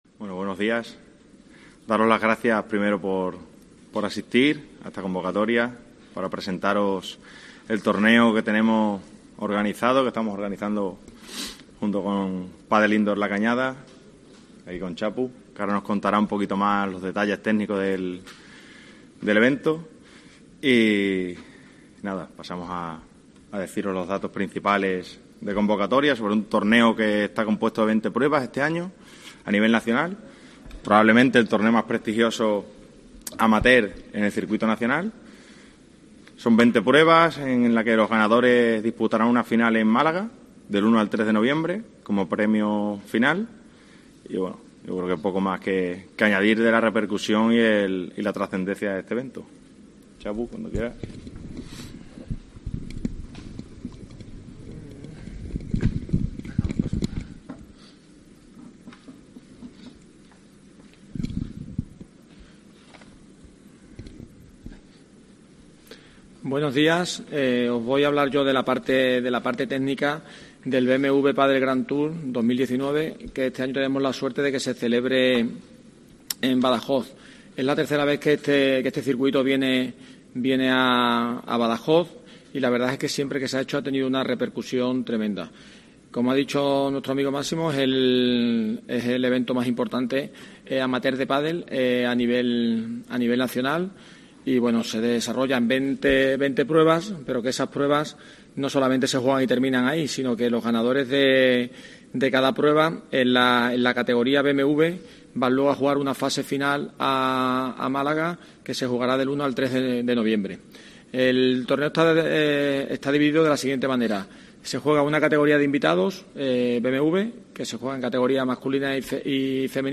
Presentación del BMW Padel Tour